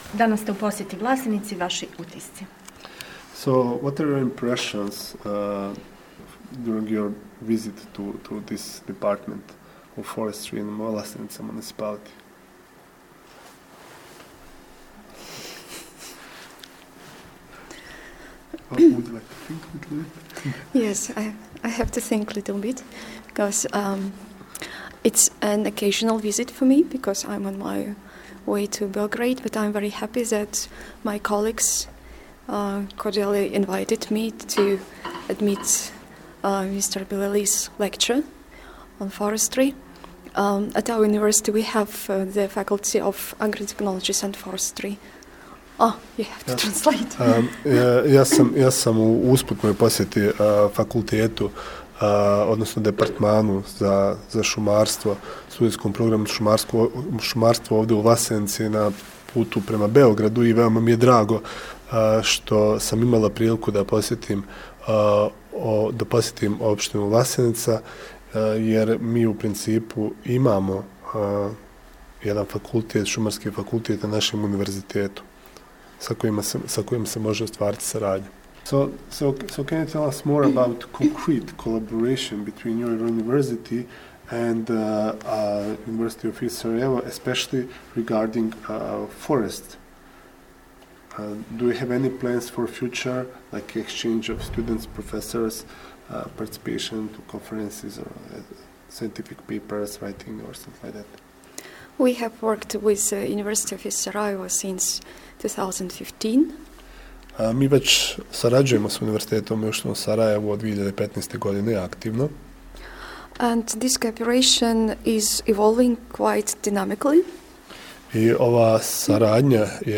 тонска изјава